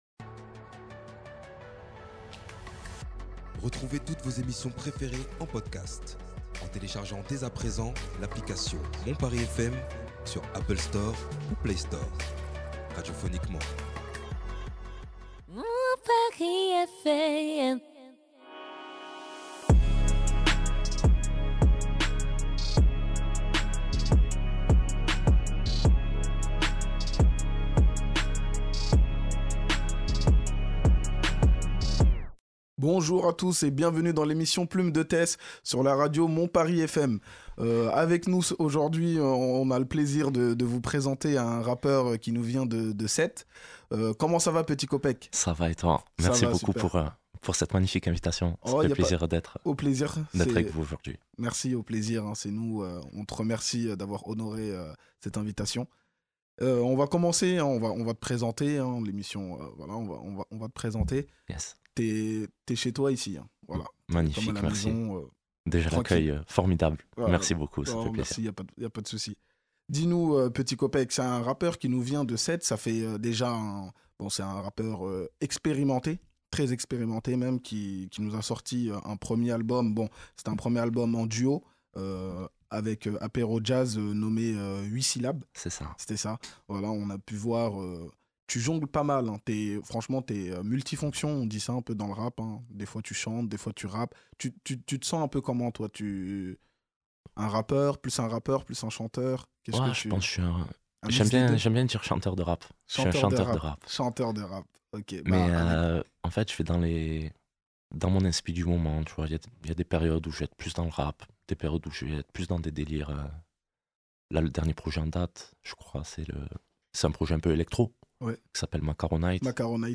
Ensemble ils retracent son parcours, sa visions du rap et ses perspectives futurs, sans bien évidemment oublier la partie live.